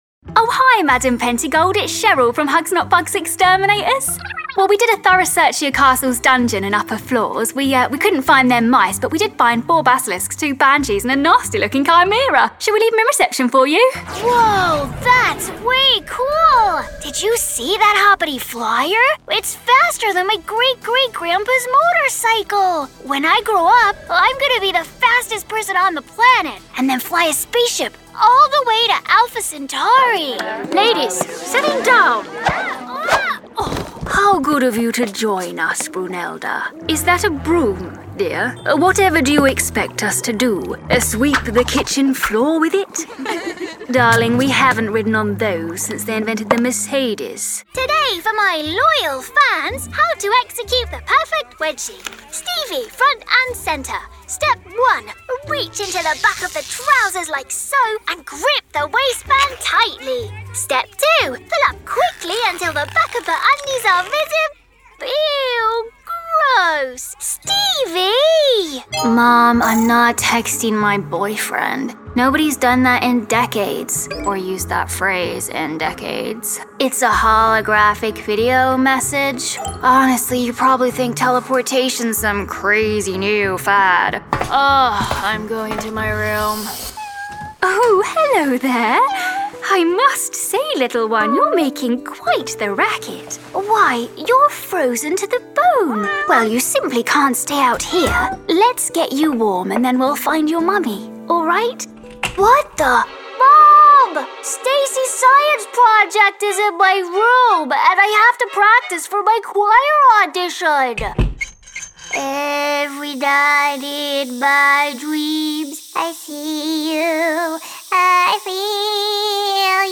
English (British)
Commercial, Young, Playful, Friendly, Soft